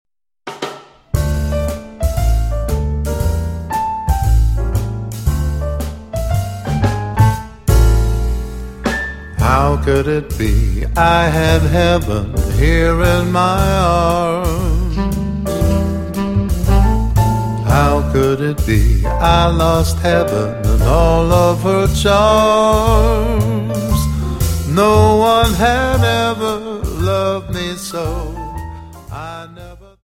Dance: Slowfox 29